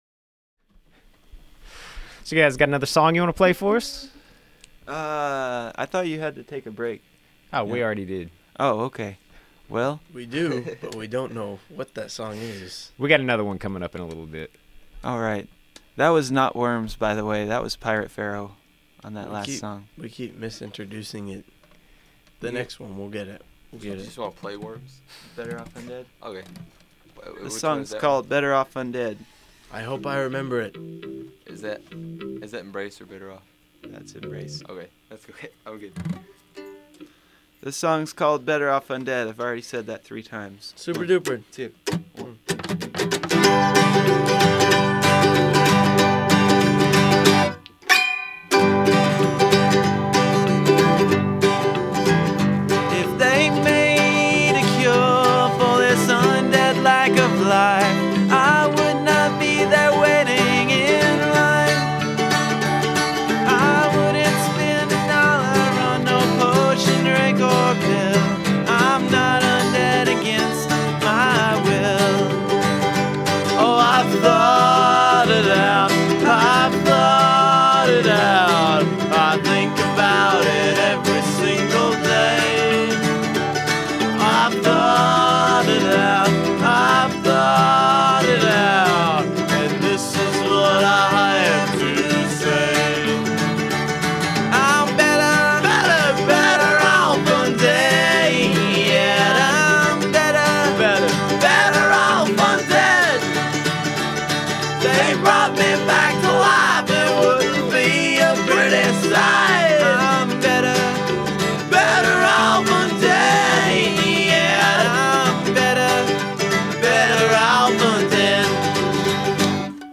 zombie rock band